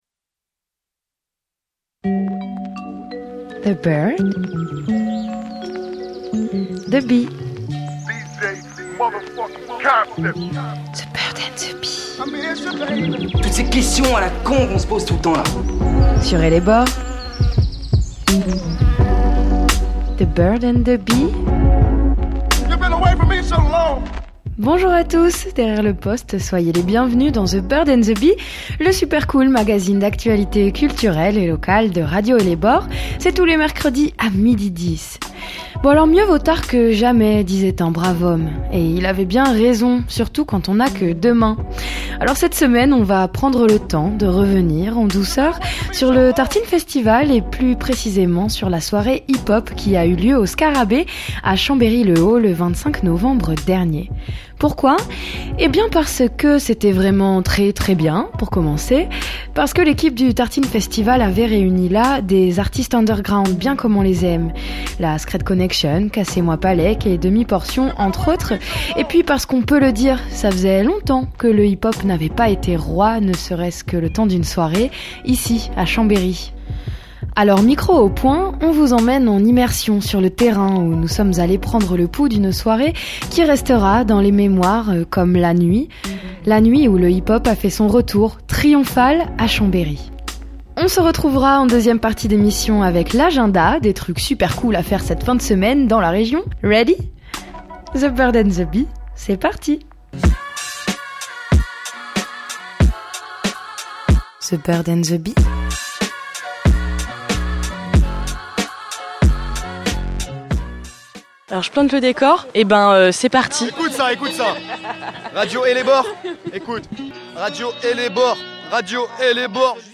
Micro au poing, The Bird & The Bee vous emmène en immersion sur le terrain, vivre ou revivre une soirée qui restera dans les mémoires : la nuit où le hip-hop a fait son retour triomphal à Chambéry.